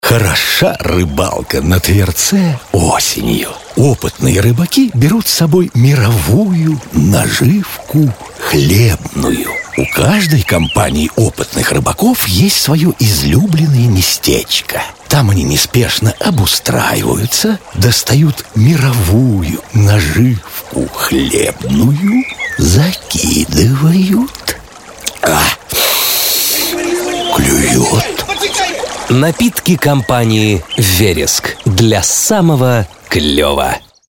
Вид рекламы: Радиореклама